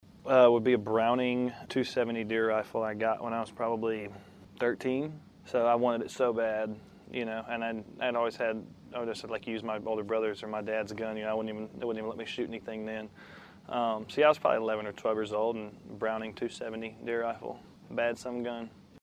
Audio / PARKER MCCOLLUM RECALLS HIS FAVORITE CHRISTMAS GIFT AS A CHILD.